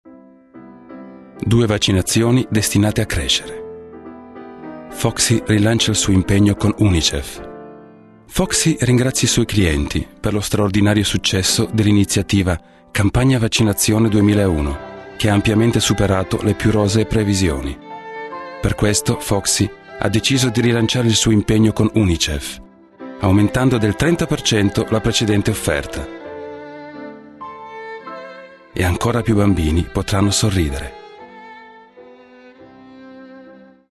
Sprecher italienisch.
Sprechprobe: Werbung (Muttersprache):
italien voice over talent